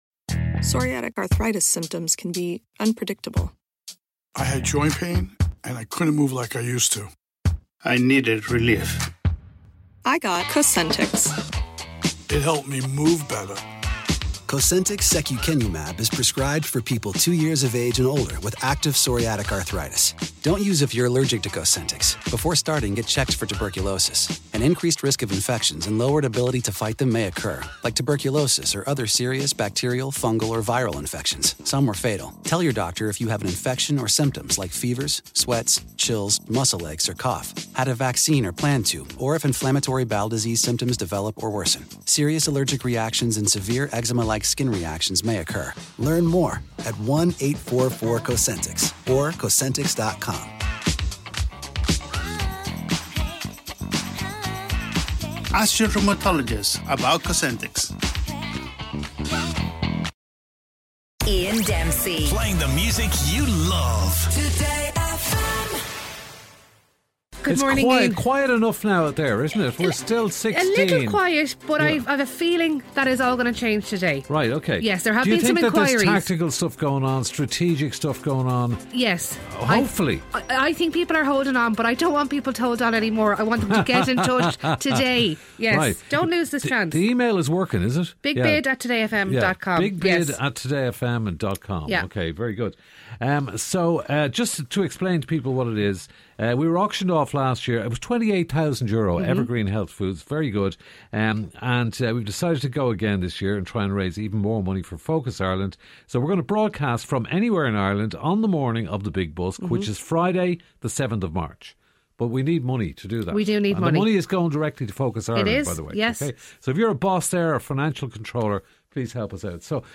With Iano at the helm, the Breakfast Show brings you up to speed on everything. News, sport, guests, giveaways, listener interaction and music - not to mention the legendary Gift Grub - all add to the mix of this 2-hour radio rollercoaster.